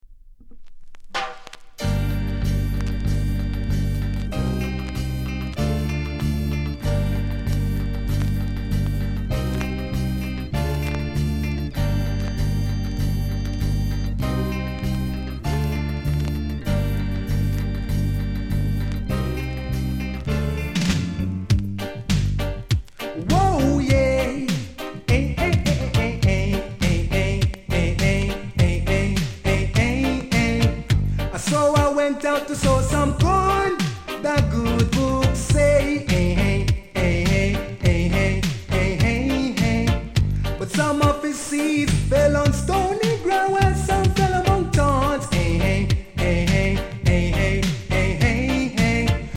vg+(noise on start)